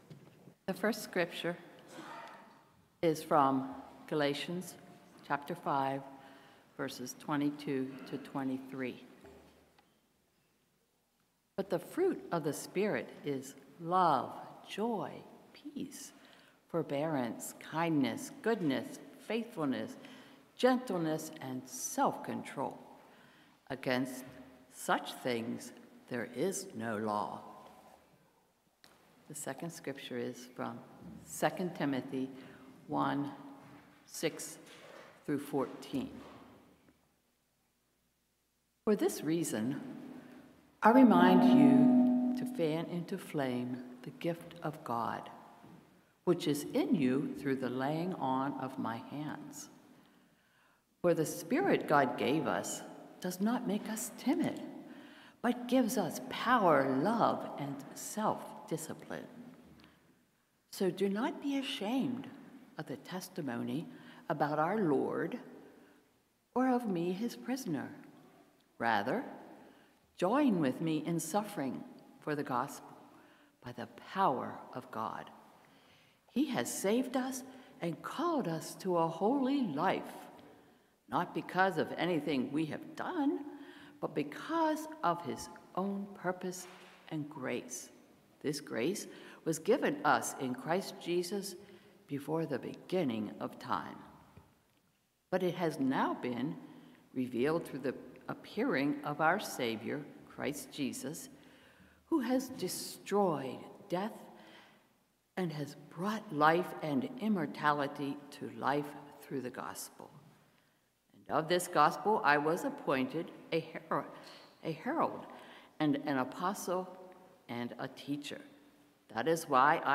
Sermons | Washington Community Fellowship
Guest Speaker